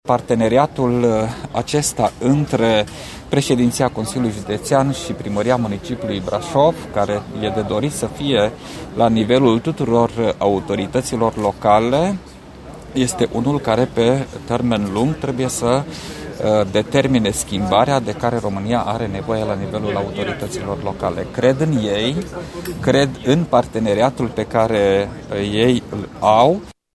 La eveniment a participat ministrul Fondurilor Europene, Ioan Marcel Boloș, care a declarat că crede în parteneriatul celor 2 lideri PNL.